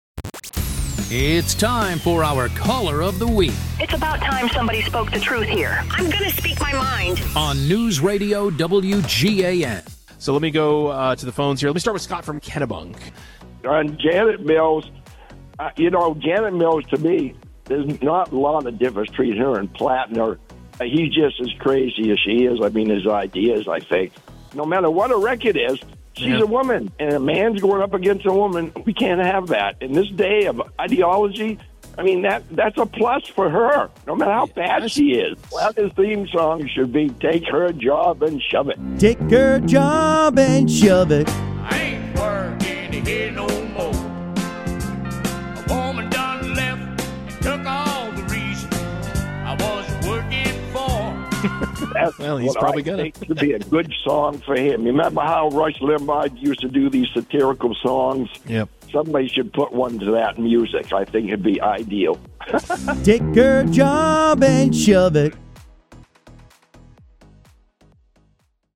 one person calling into the Morning News, who offers an interesting take, a great question or otherwise bring something to the discussion that is missing.